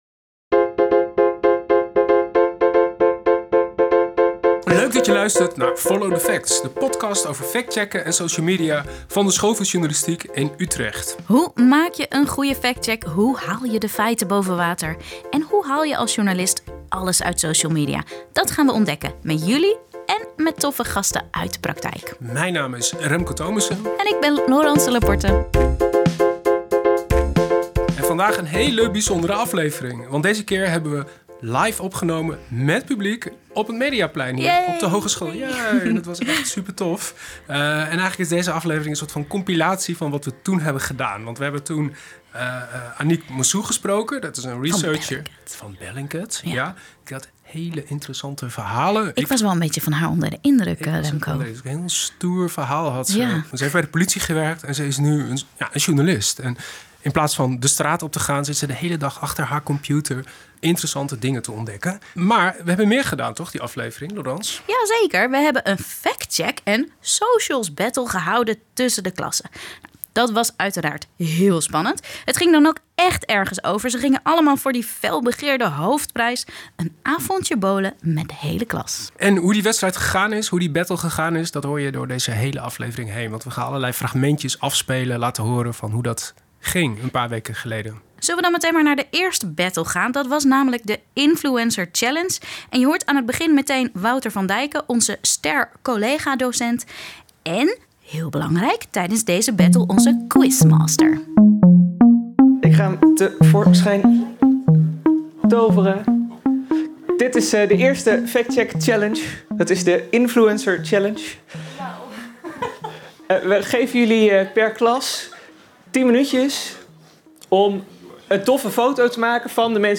De afsluitende aflevering van Follow the Facts werd live opgenomen op het Mediaplein in de School voor Journalistiek in Utrecht. Studenten uit de verschillende klassen gingen de strijd aan met elkaar in drie spectaculaire challenges.